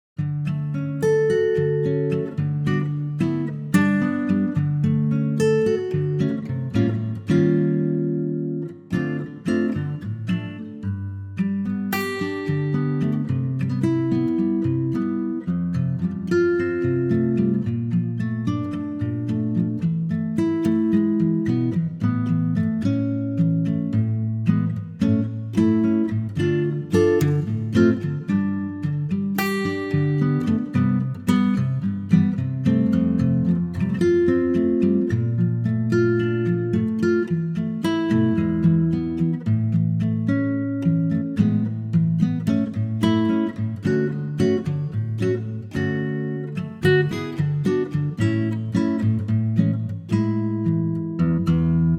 key - Dm & A (chorus) - vocal range - E to A
Stunning acoustic guitar arrangement
backing track downloads